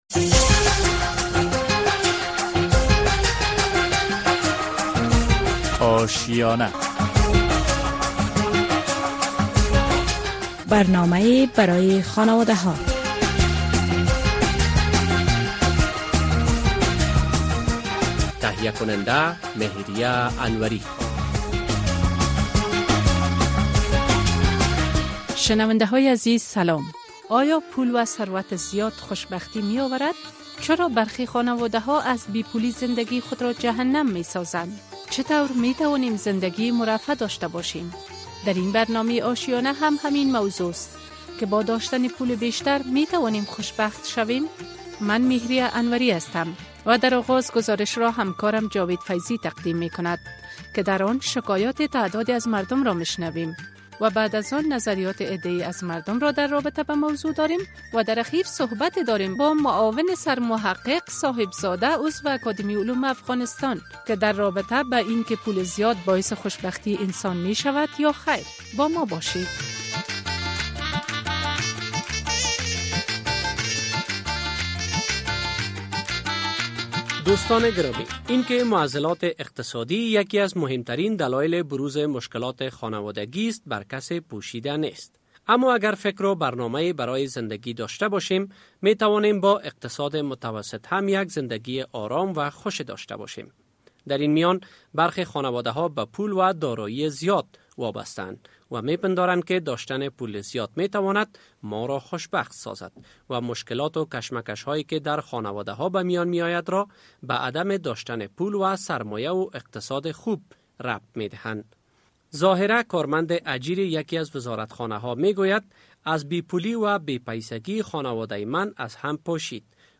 این برنامه آشیانه به همین موضوع اختصاص یافته که خشک کردن بعضی خوراکه‌ها چه فواید و ضررهایی دارد. نخست گزارشی در این مورد تقدیم می‌شود که خانواده‌ها در فصل خزان کدام خوراکه‌ها را خشک می‌کنند؟